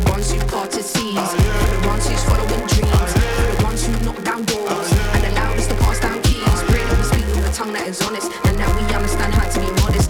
Output of my Audio DK is noisy/clipping when coming via i2s - Nordic Q&A - Nordic DevZone - Nordic DevZone
The data on DOUT, Codec->MCU is crystal clear and - as expected - the data from MCU->Codec on DIN is noisy.
I'll attach the noisy sample (just 10sec of an arbitrary radio station audio stream I fed into LineIn, not usually my type of music ;-) as well as my current app which is based on your demo app.